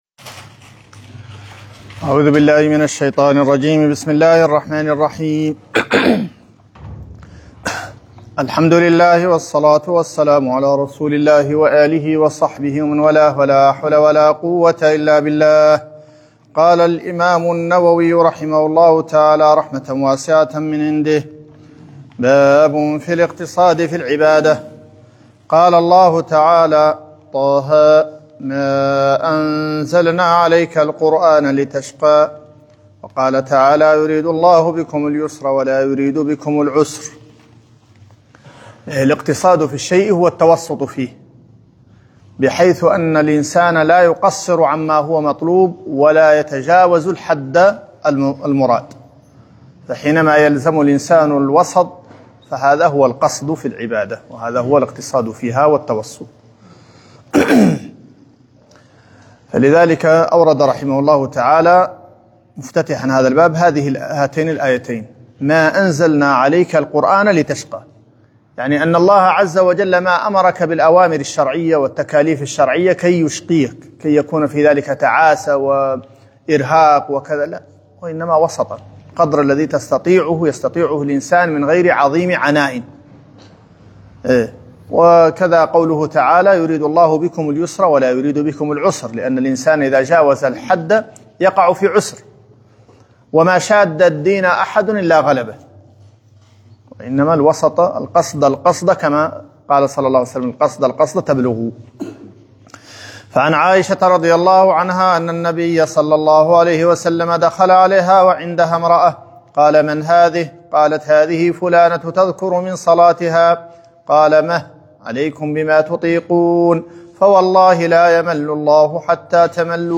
شرح كتاب رياض الصالحين